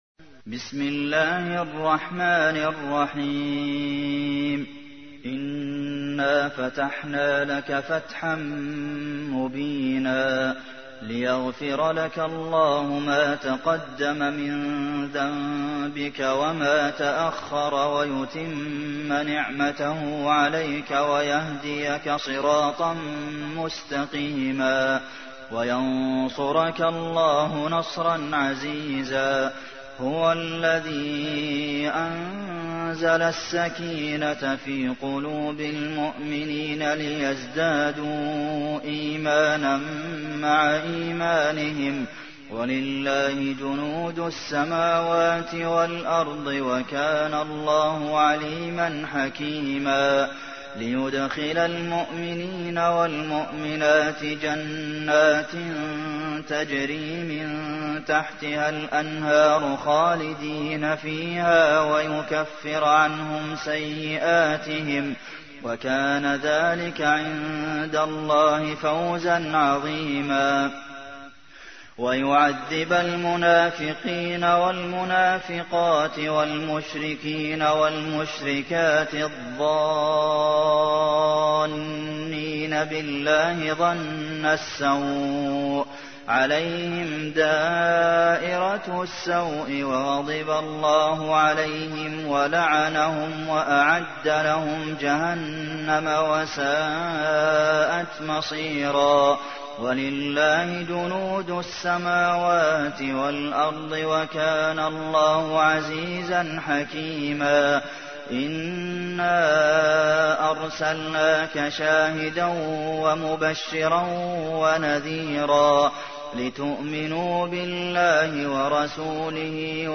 تحميل : 48. سورة الفتح / القارئ عبد المحسن قاسم / القرآن الكريم / موقع يا حسين